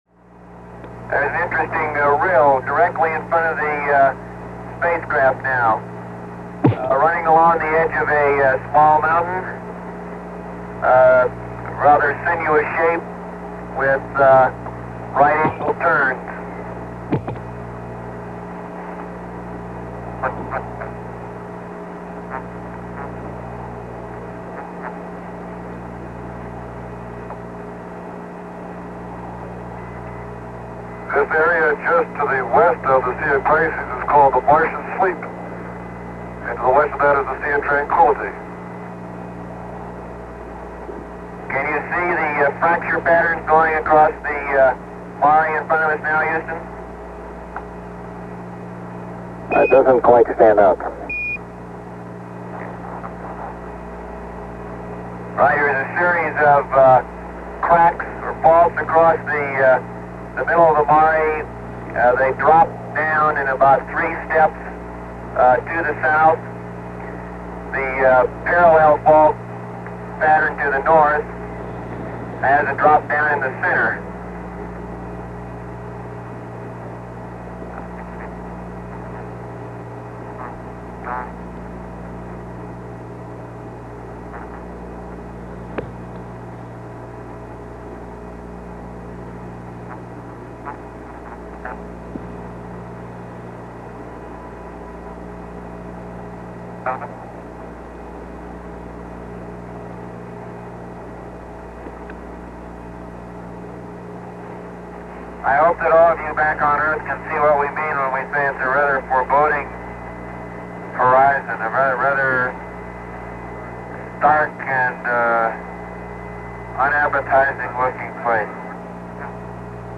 Coming to the end of a year of chaos and upheaval in 1968, a glimmer of hope came from a space capsule orbiting the moon on December 24, 1968.
And the crew of Apollo 8 took turns reading a passage from The Book of Genesis, and finished by wishing everyone on Earth a Merry Christmas.
Here is that complete Christmas Eve message from Frank Borman, James Lovell and William Anders – the crew of Apollo 8, on the night of December 24, 1968